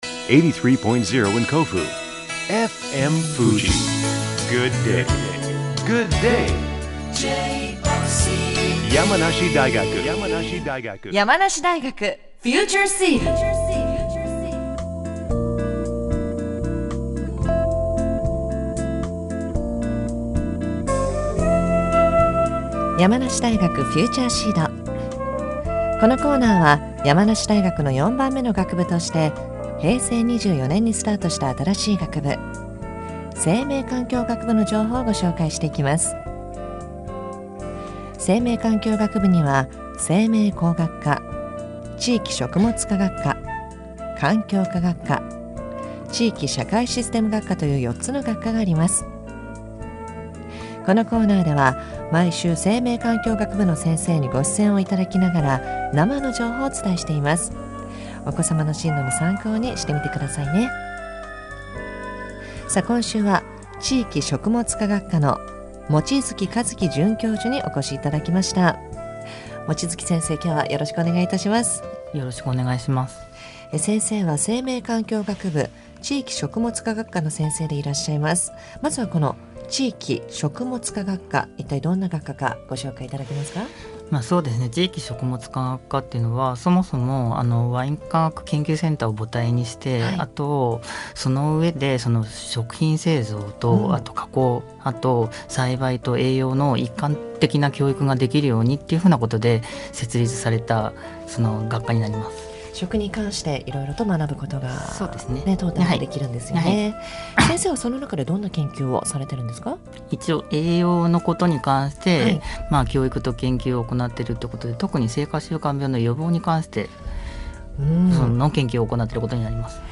毎週木曜日11時10分頃から放送中の「山梨大学　FUTURE SEED」